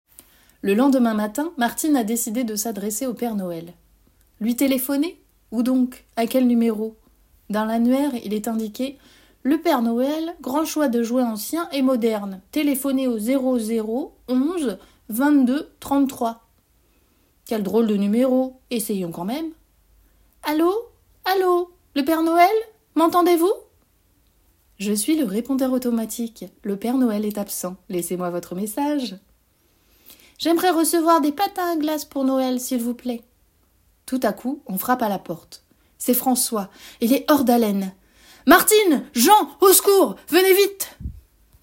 27 - 49 ans - Mezzo-soprano